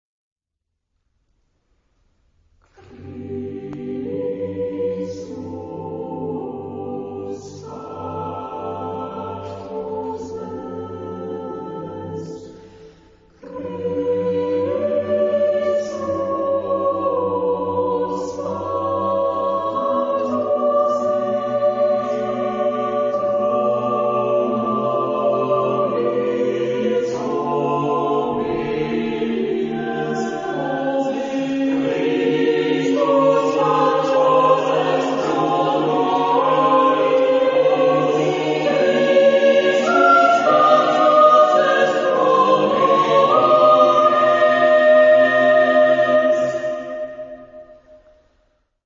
Genre-Style-Form: Motet ; Sacred
Mood of the piece: andante
Type of Choir: SATB  (4 mixed voices )
Instruments: Organ (1)
Tonality: G major ; various